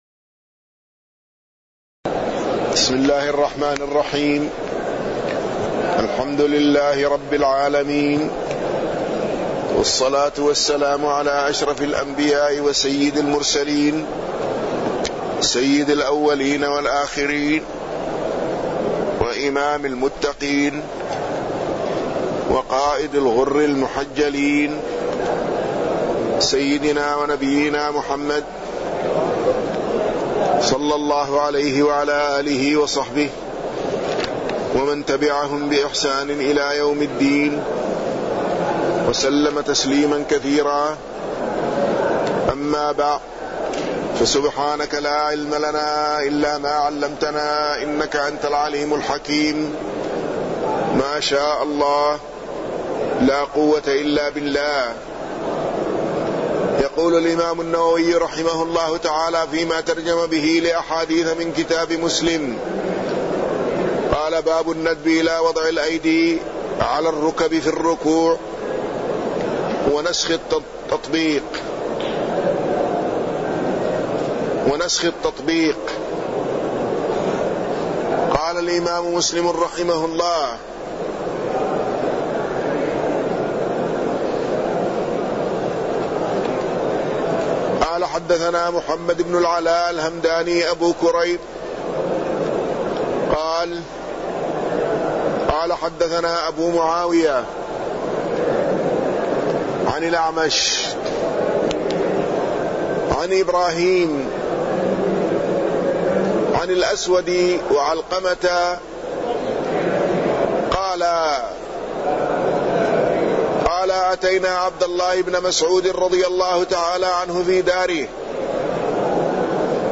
تاريخ النشر ٢٩ ربيع الثاني ١٤٢٩ هـ المكان: المسجد النبوي الشيخ